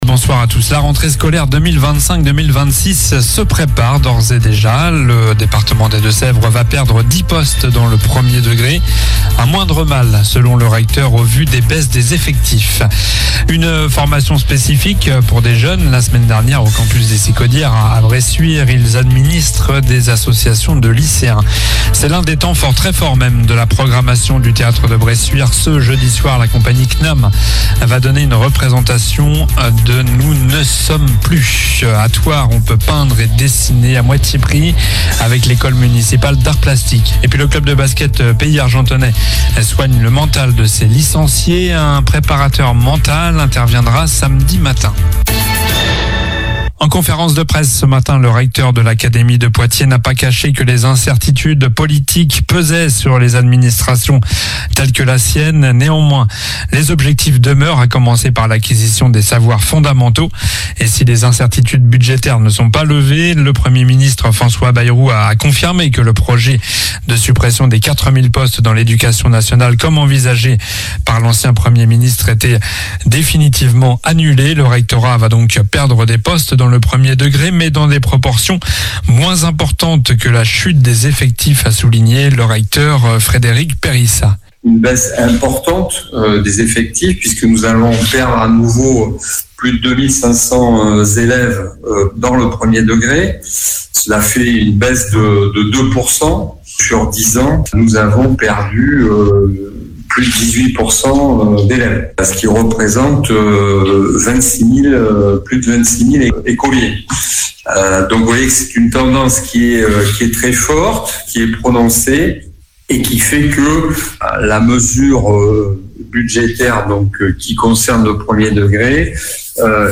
Journal du mardi 28 janvier (soir)